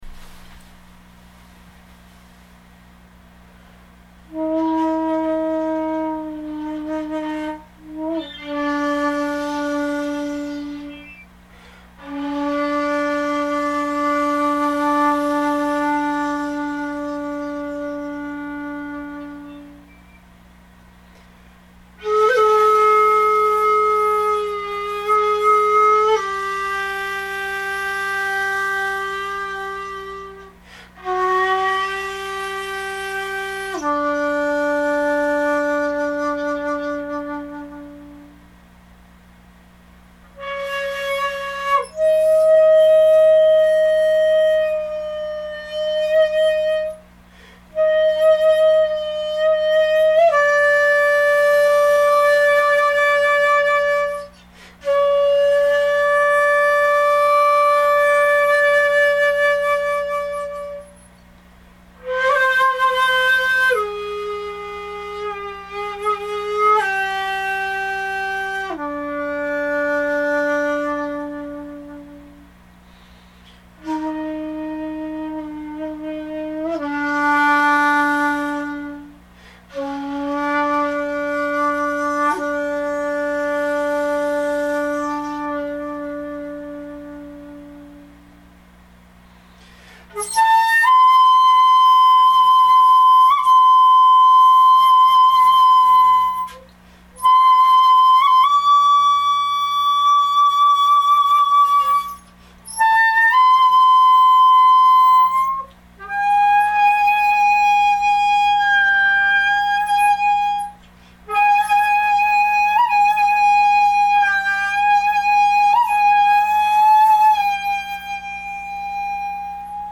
最後に、また拙い演奏掲載しておきます。